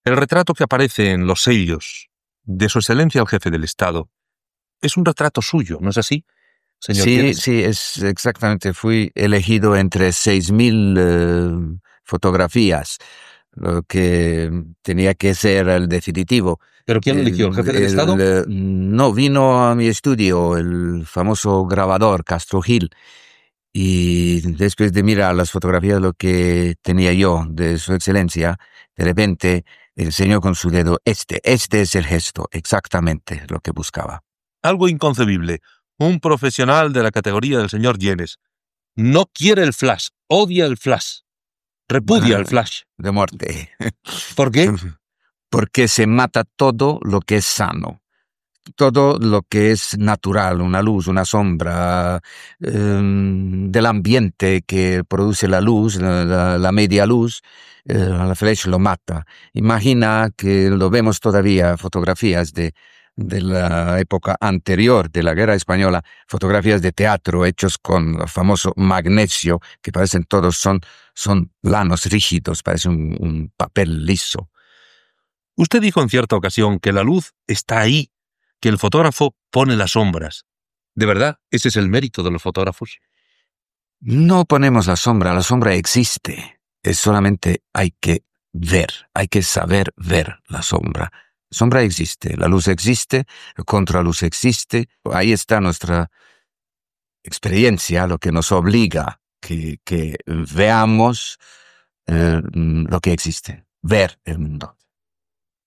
Entrevista al fotògraf Juan Gyenes sobre el retrat que va fer al cap d'Estat Francisco Franco per fer alguns dels segells on sortia el seu bust